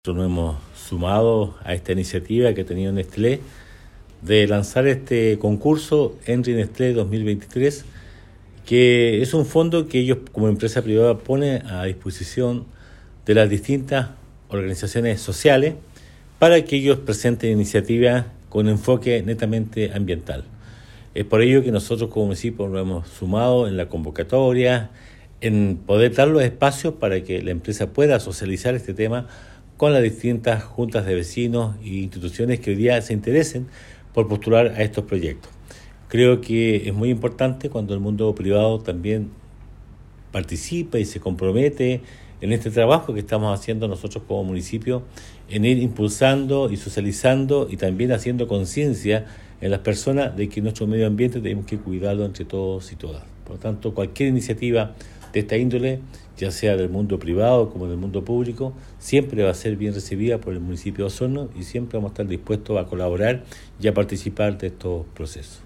En la Sala de Sesiones de la Municipalidad de Osorno, se realizó el lanzamiento del Fondo de Desarrollo Local Henri Nestlé.
El Alcalde Emeterio Carrillo, agradeció la disposición del sector privado para el desarrollo de las organizaciones sociales ya que de este modo se puede impulsar temáticas tan importantes como la sustentabilidad.